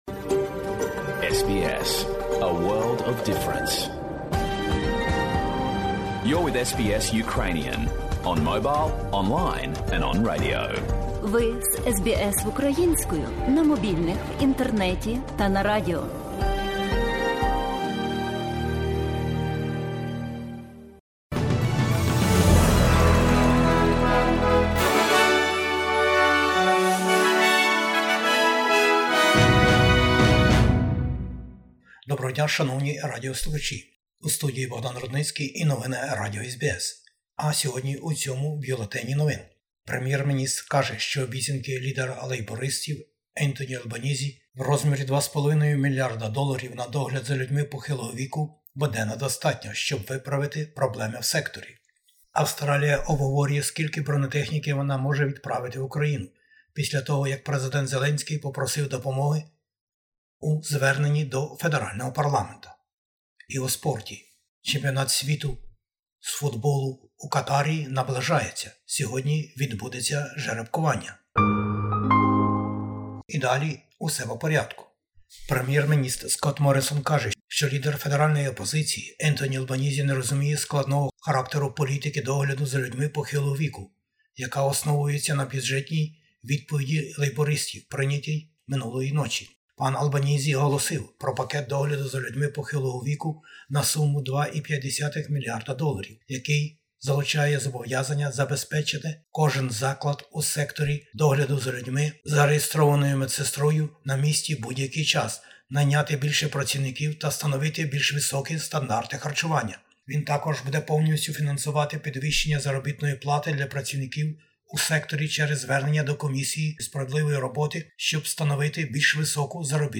Бюлетень новин SBS українською. Австралія - Україна: відеозвернення Президента України та відповіді лідерів правлячої коаліції та опозиції. Бюджет Федеральної опозиції також представлено для австралійців услід за Урядом Австралії.